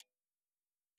click_low.wav (171 KiB) downloaded 79 time(s).
click_low_wav